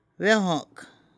Stress falls on the initial syllable of the root word.
vvine